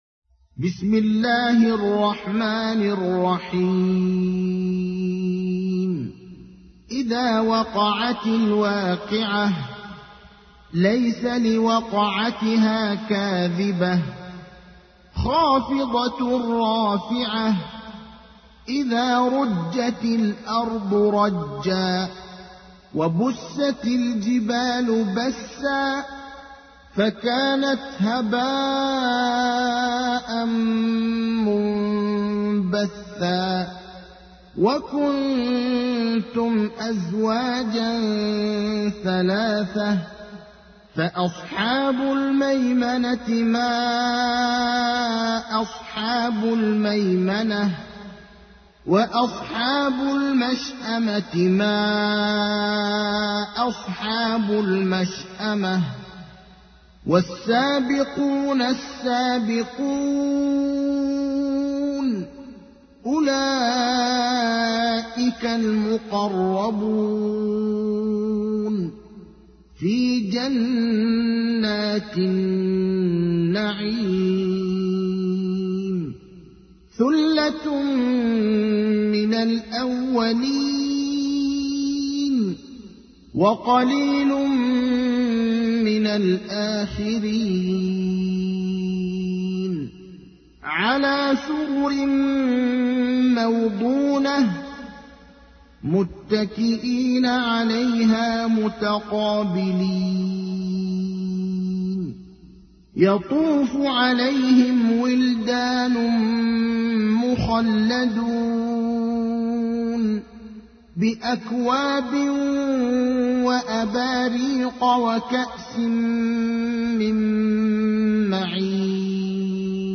تحميل : 56. سورة الواقعة / القارئ ابراهيم الأخضر / القرآن الكريم / موقع يا حسين